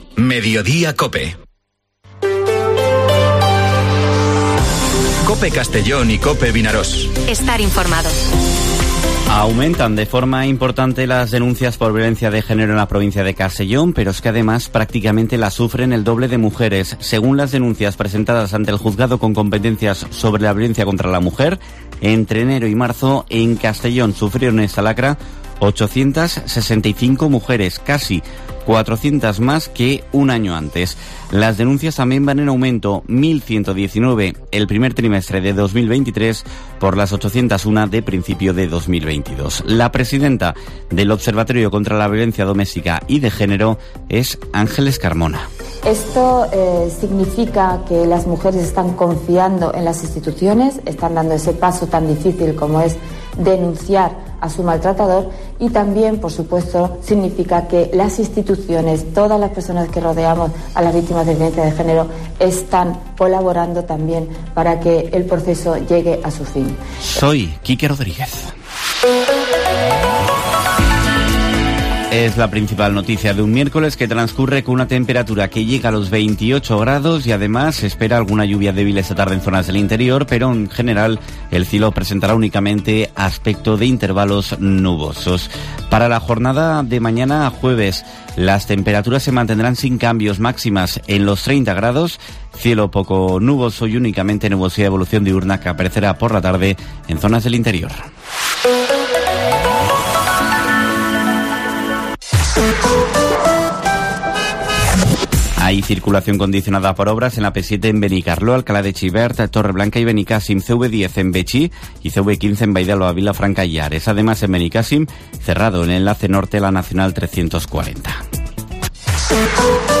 Informativo Mediodía COPE en la provincia de Castellón (14/06/2023)